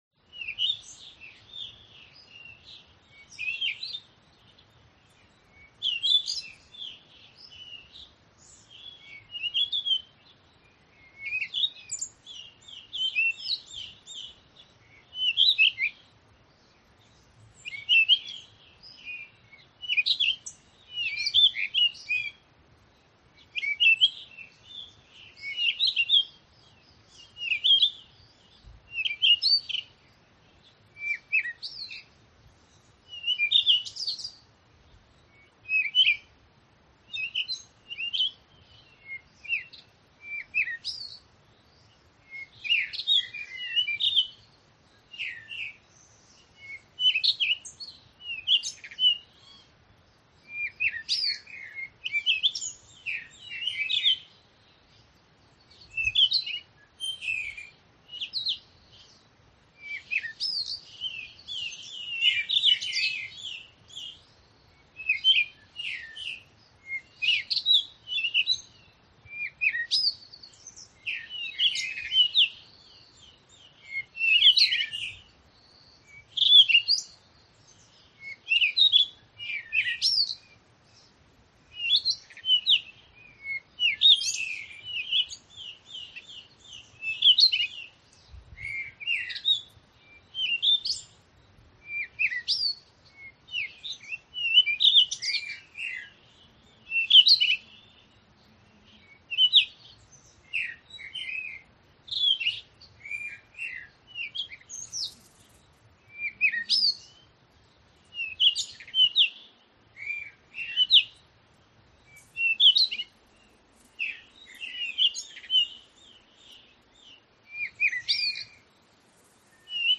Здесь собраны натуральные записи солнечных дней: легкий ветер в кронах деревьев, стрекотание кузнечиков, плеск воды и другие уютные звучания.
Шум приятной погоды за окном